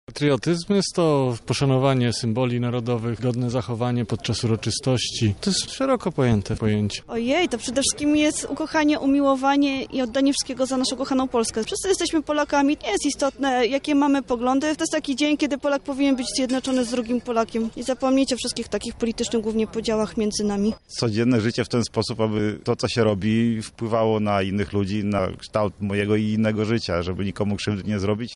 Sonda uliczna dotycząca patriotyzmu
Zapytaliśmy mieszkańców Lublina czym jest dla nich: